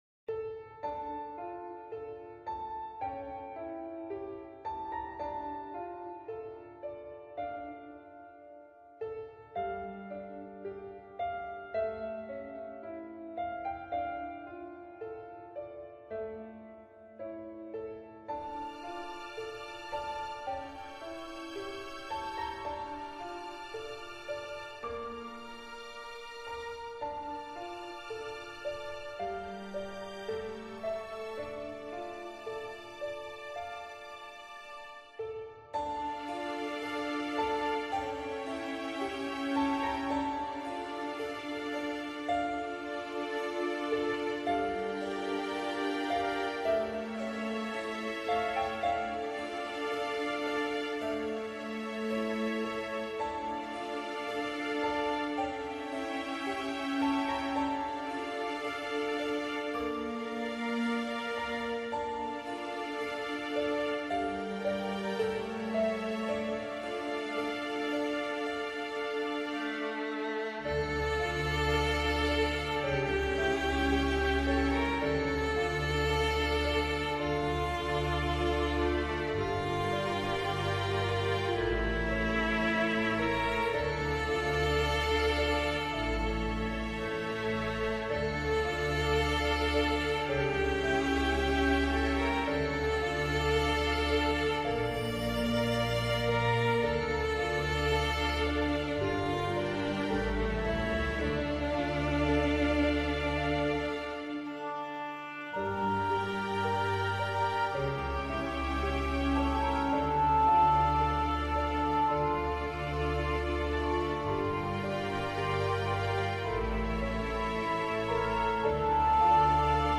musique-pour-chats-et-chatons-musique-relaxante-pour-dormir-les-chats.mp3